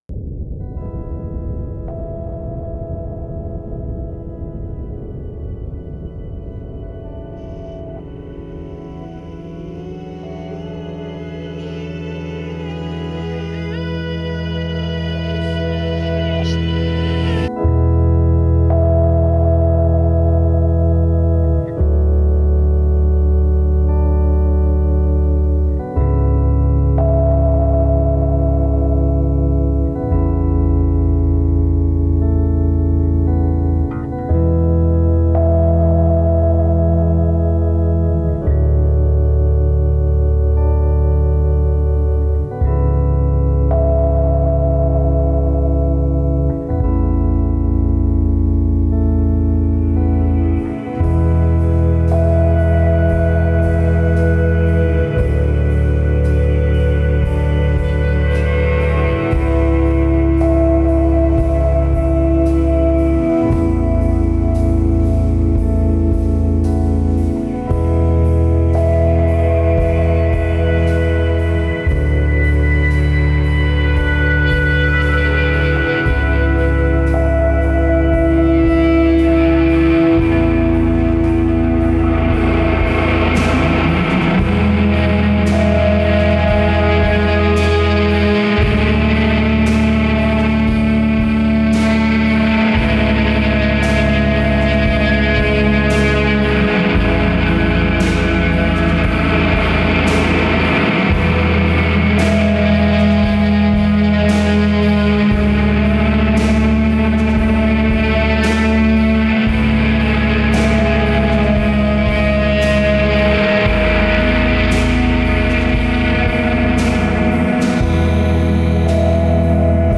Post rock